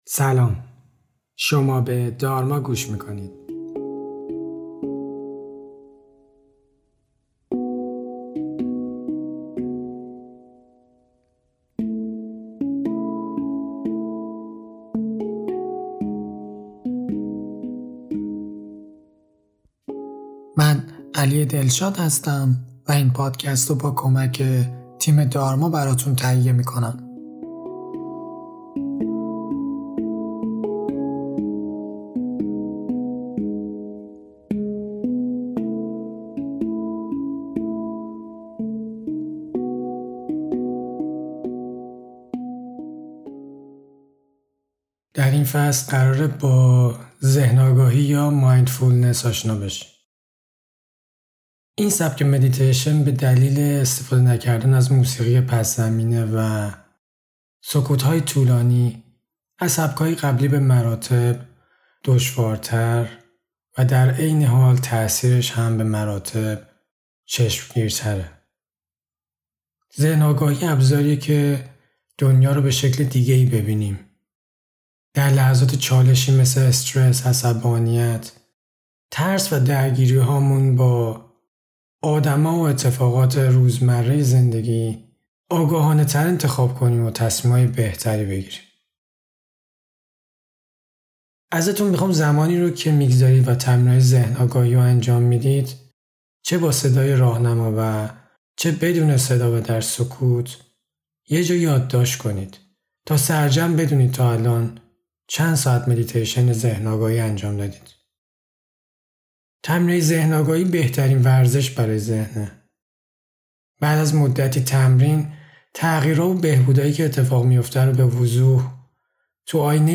این سبک مدیتیشن به دلیل استفاده نکردن از موسیقی پس‌زمینه و سکوت‌های طولانی، از سبک‌های قبلی به مراتب دشوارتره، در عین حال تأثیرش هم به مراتب چشمگیرتره.